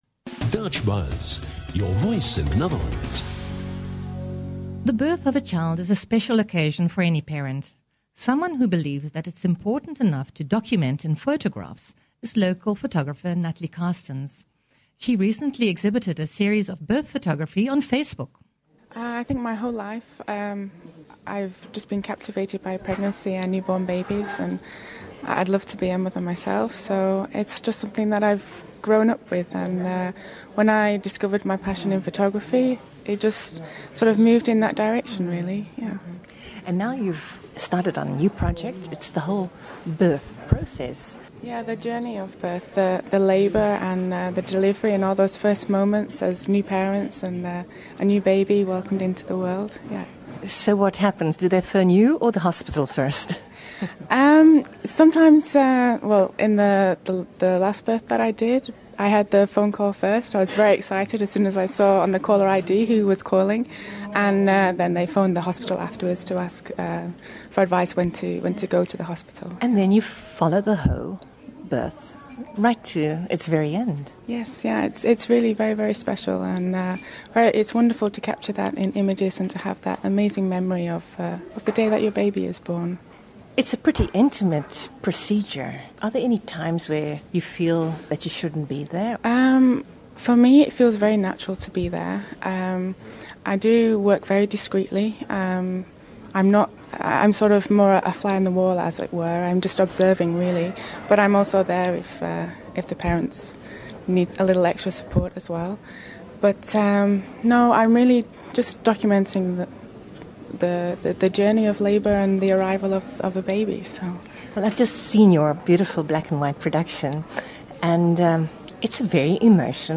Radio Interview about Birth Photography featured on DutchBuzz, Den Haag FM
birth-photography-interview.mp3